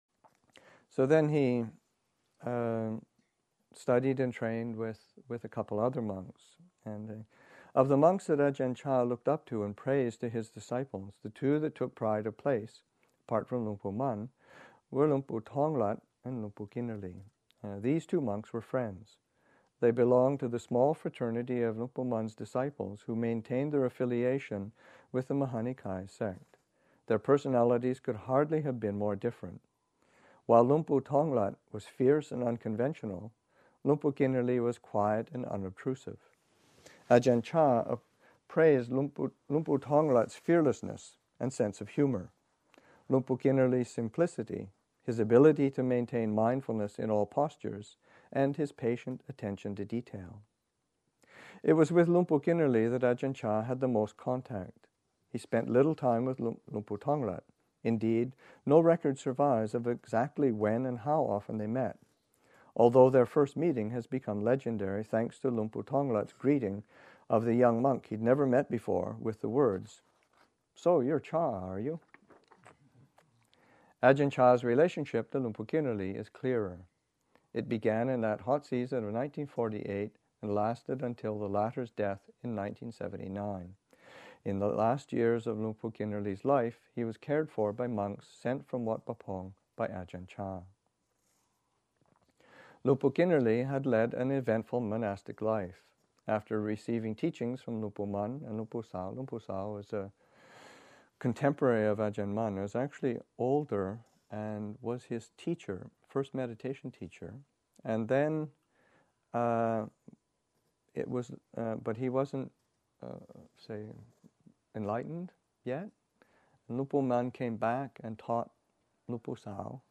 Reading from the draft biography